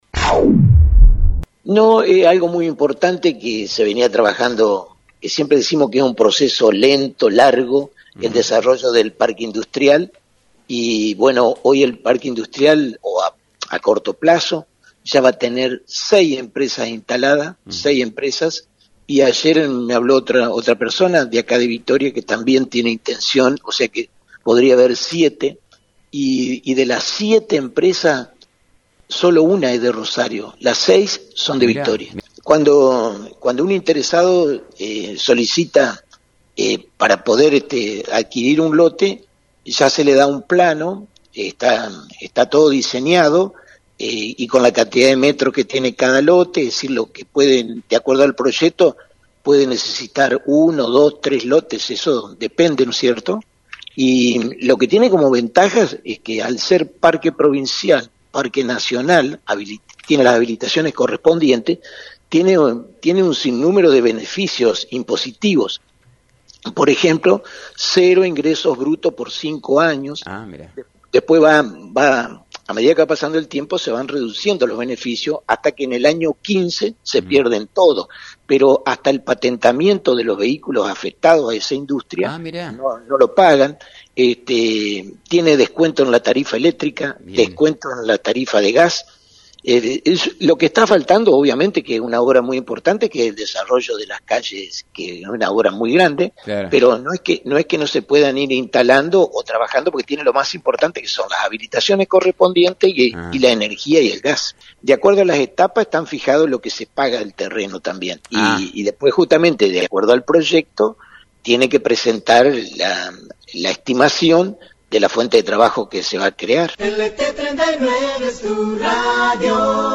Intendente Mingo Maiocco para LT39